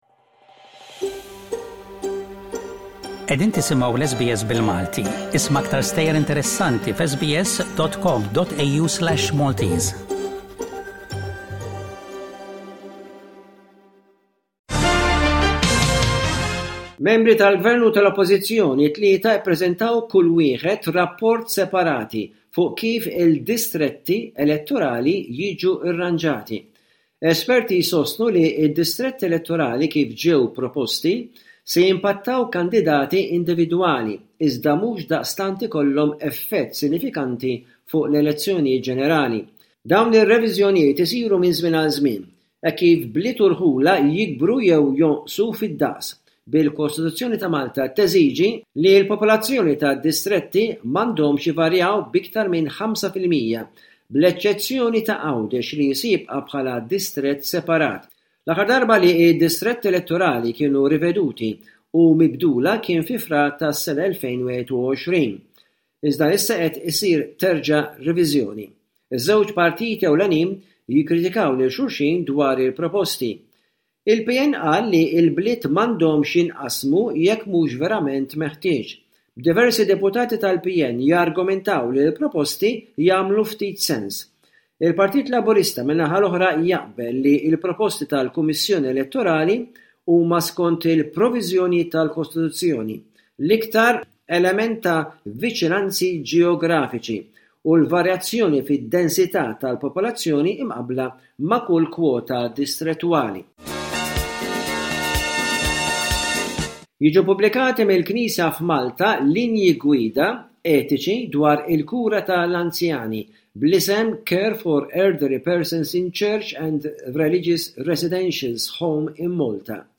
Bullettin ta' aħbarijiet minn Malta mill-korrispondent tal-SBS